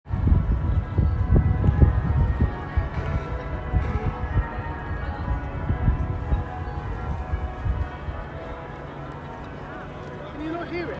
5. Cathedral bells